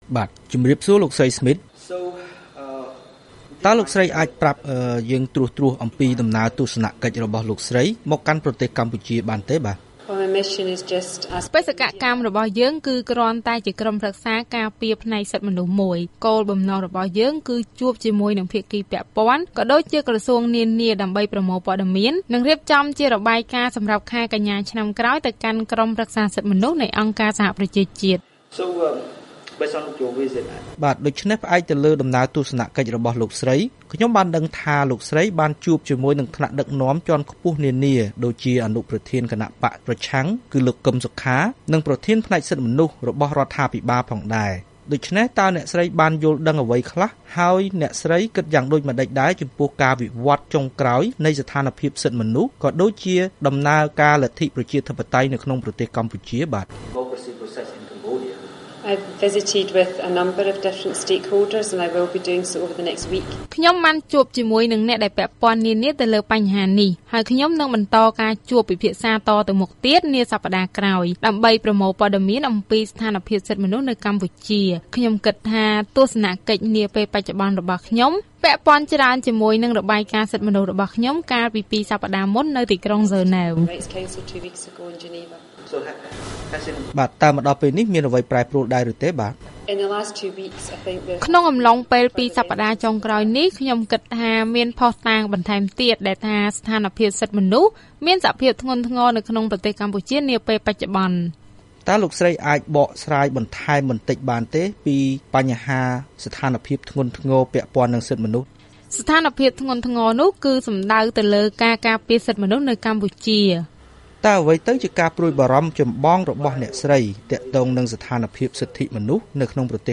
បទសម្ភាសន៍ VOA៖ ប្រេសិតពិសេសអ.ស.ប.និយាយអំពីស្ថានភាពសិទ្ធិមនុស្សនៅកម្ពុជា